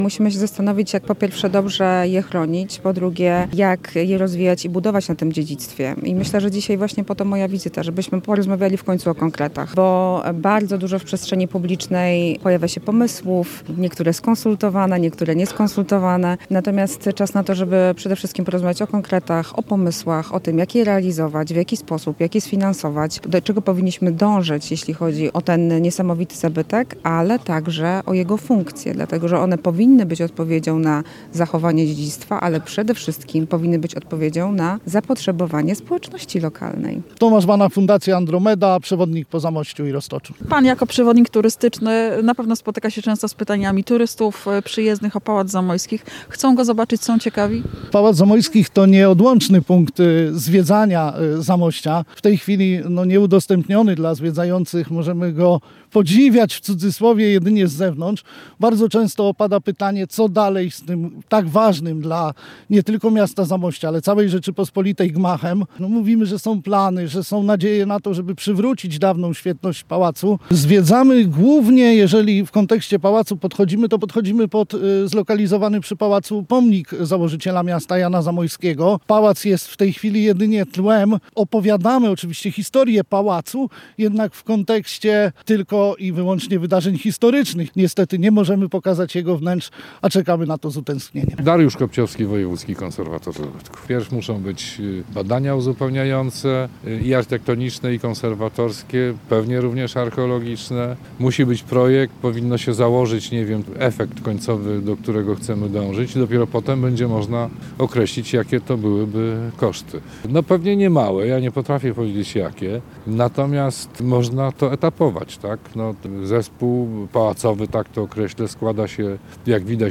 Głównym gościem wydarzenia była ministra kultury i dziedzictwa narodowego Marta Cienkowska, która w rozmowie z Radiem Lublin zadeklarowała pomoc i wsparcie w ratowaniu dawnej rezydencji rodu Zamoyskich, stanowiącej dobro narodowe.